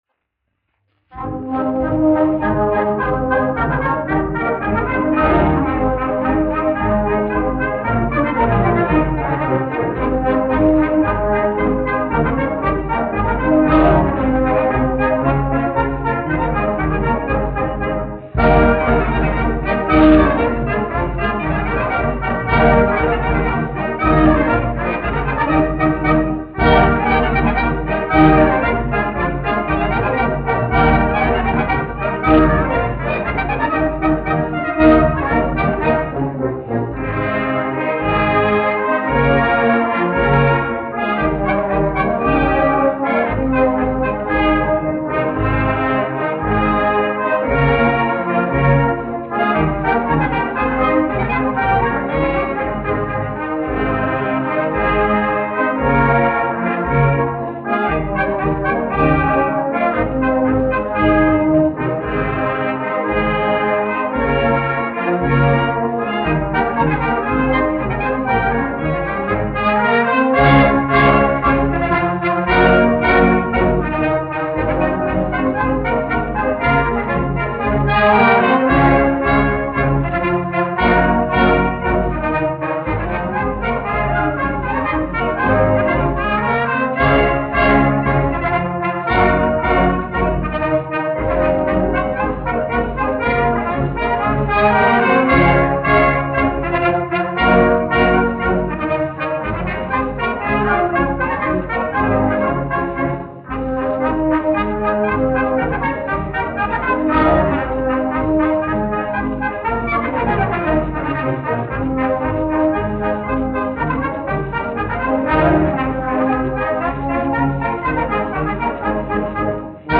1 skpl. : analogs, 78 apgr/min, mono ; 25 cm
Polkas
Pūtēju orķestra mūzika
Skaņuplate